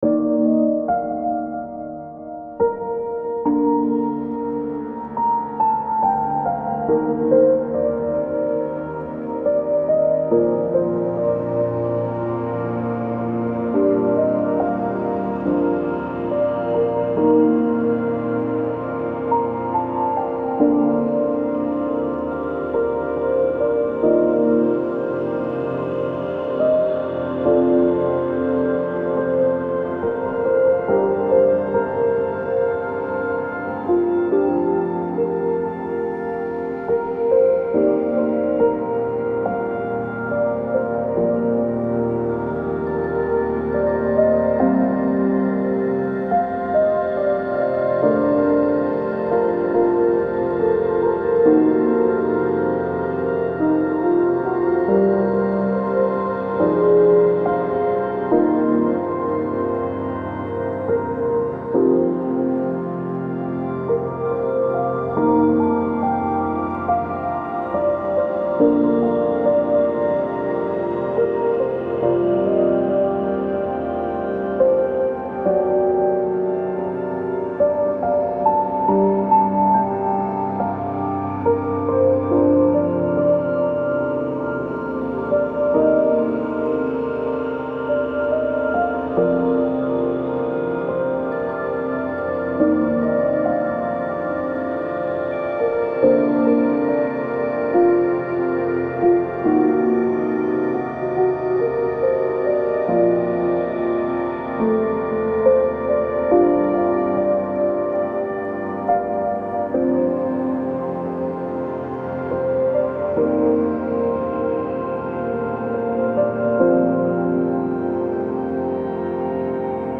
不思議 幻想的 懐かしい 浮遊感 穏やか 青春